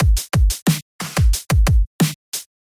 描述：工厂里的一天......用果味循环制作的电子节拍
Tag: 90 bpm Electro Loops Drum Loops 459.55 KB wav Key : Unknown